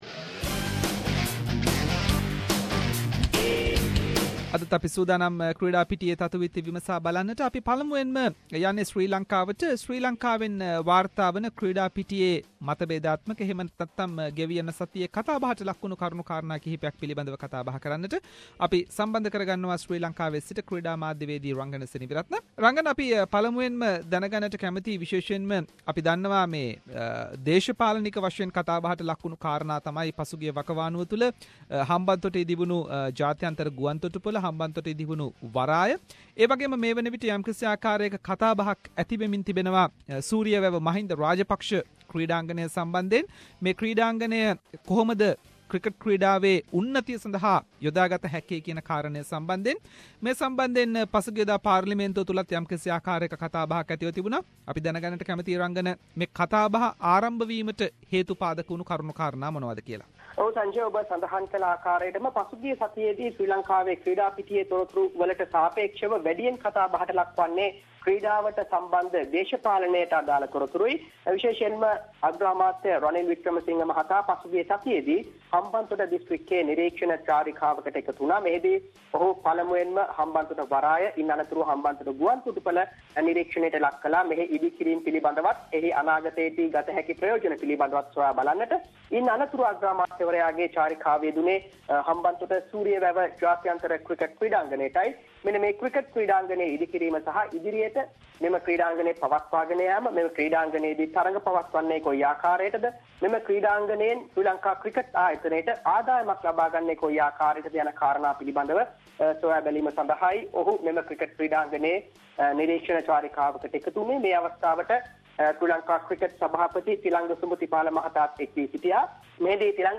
SBS Sinhala